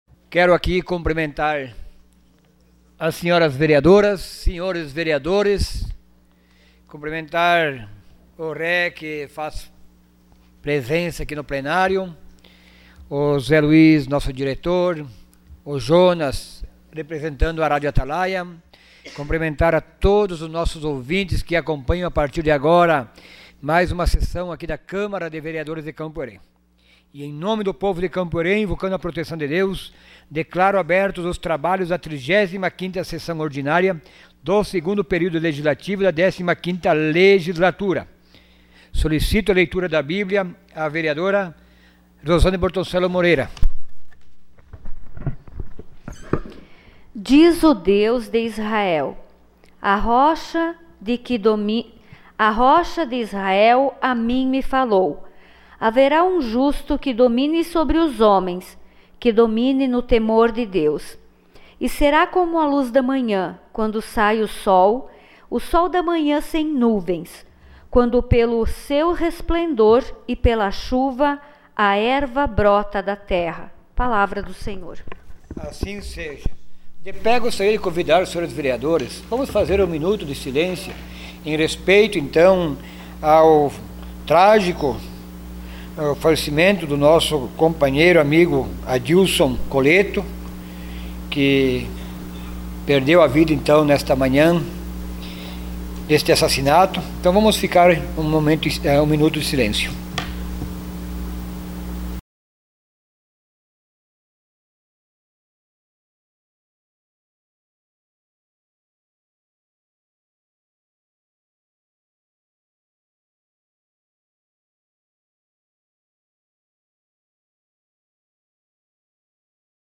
Sessão Ordinária dia 09 de agosto de 2018.